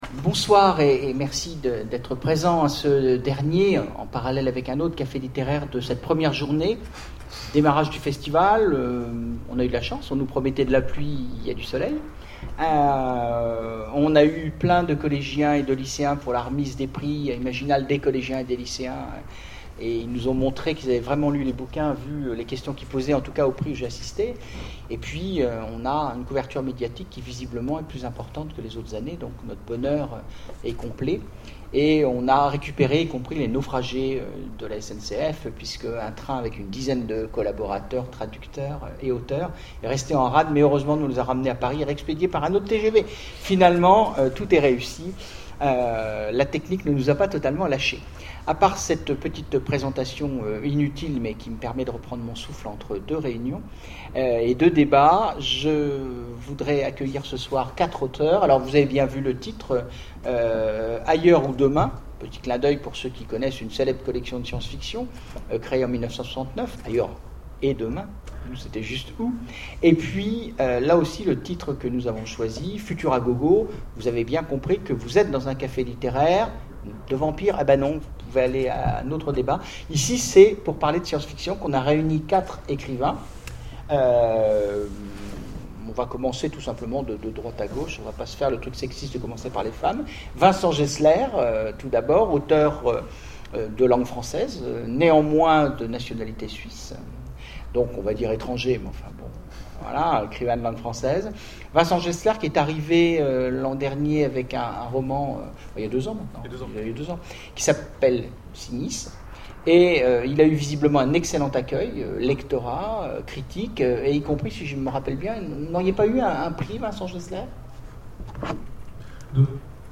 Imaginales 2012 : Conférence Ailleurs ou demain...